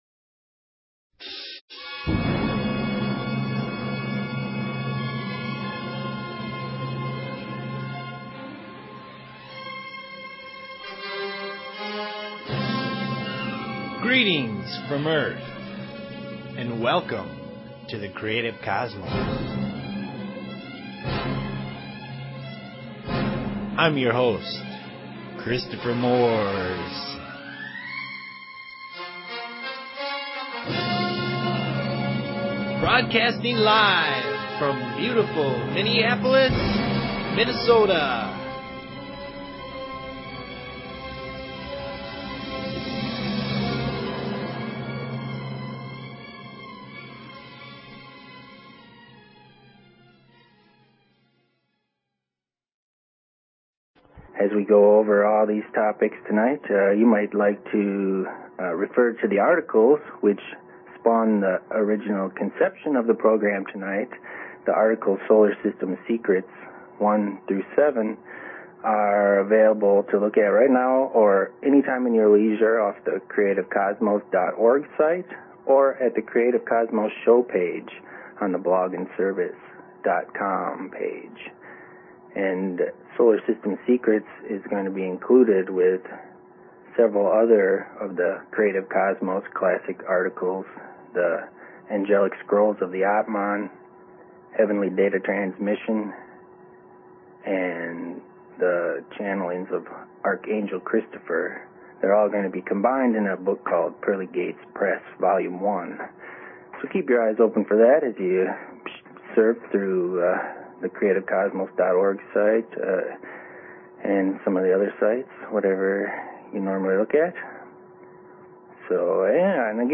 Zen, Chakras, Dreams, Astral Plane, Aliens, Spirit Communication, Past Lives, and Soul Awakening are all in play. There are no boundaries as the conversation goes where it needs to in the moment.